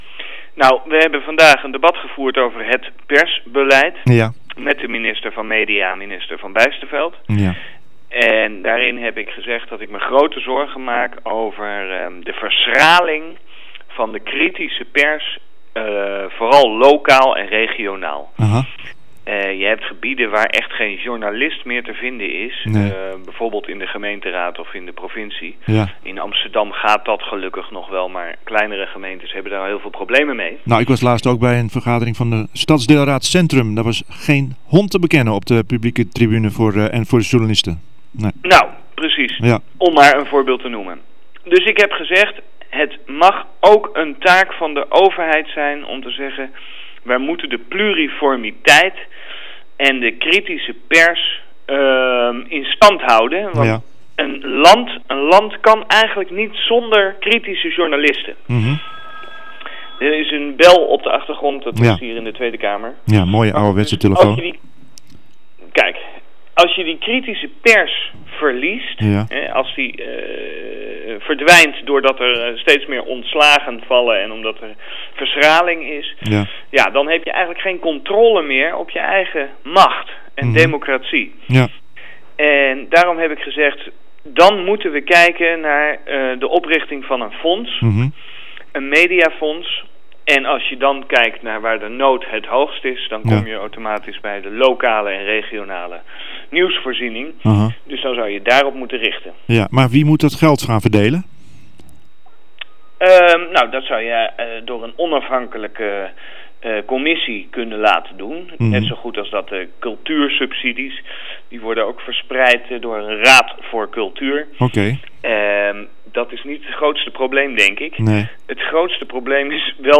SP-kamerlid Jasper van Dijk wil dat er een fonds komt om lokale journalistiek te stimuleren, ook moeten lokale media meer gaan samenwerken volgens Van Dijk. Wij vroegen hem onder neer of het niet gevaarlijk is om de overheid zo’n grote rol te laten spelen in de media.